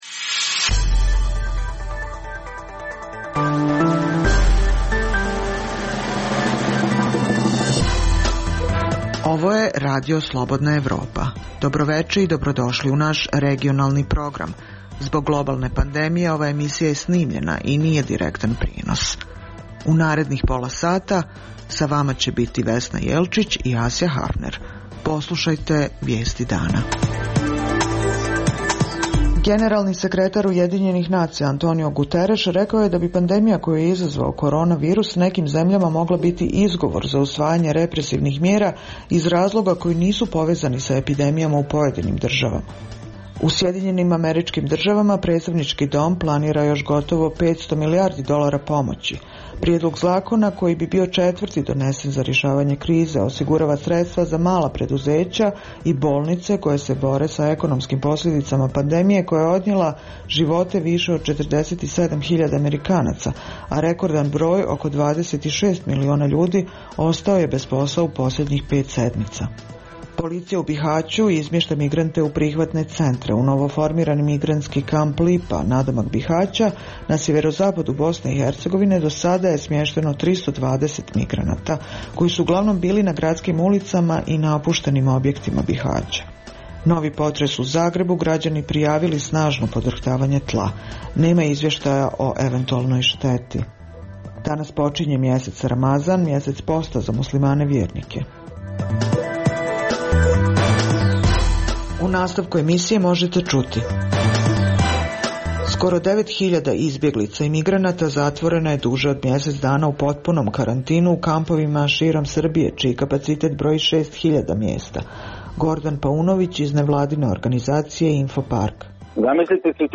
Zbog globalne pandemije, ova je emisija unapred snimljena i nije direktan prenos